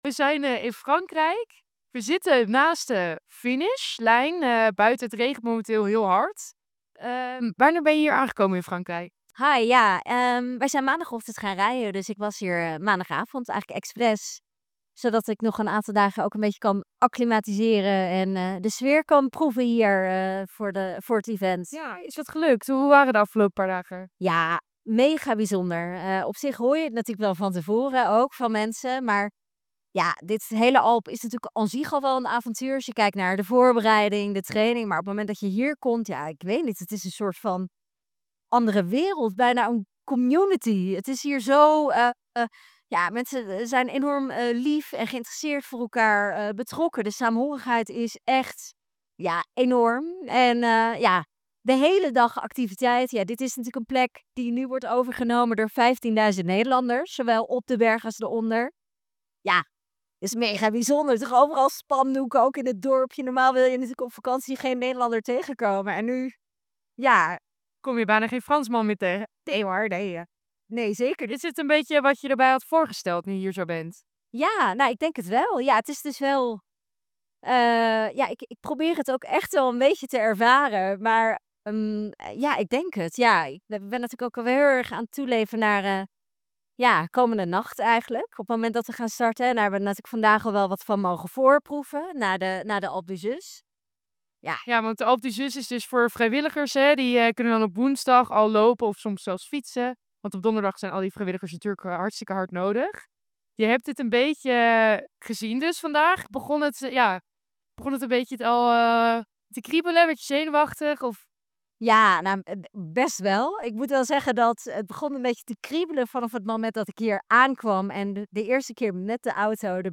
Verslaggever
in gesprek met